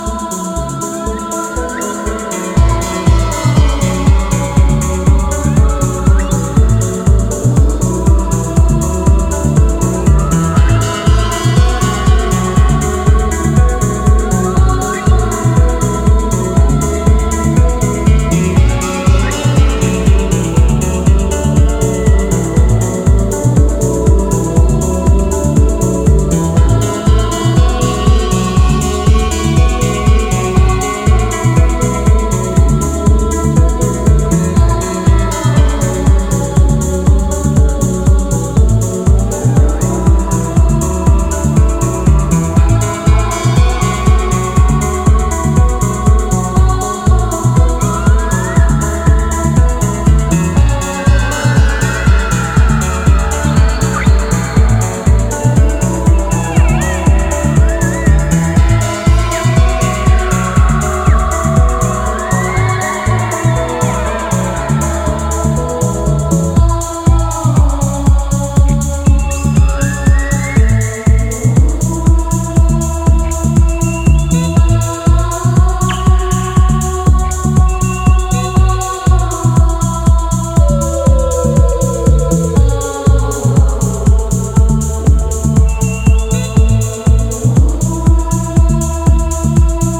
今回は、爽やかに心地良く高揚していくメロウでバレアリックなフィーリングのエレクトロニック・ハウスを展開。
ジャンル(スタイル) DEEP HOUSE